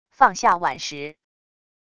放下碗时wav音频